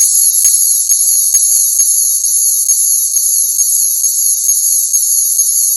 The key gimmick for this line beyond the motor is the flashing lights and irritating siren sound.
The sound chip is clearly a generic box of sounds.
Black Vehicle's Siren Sound
galaxy-transformer-light-sound-black.mp3